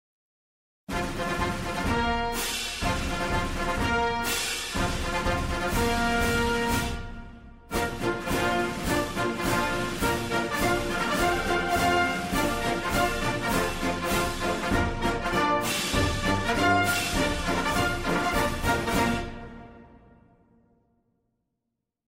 When the corps commander arrived, the band played
3-Ruffles-and-Flourishes-Generals-March-Halved.mp3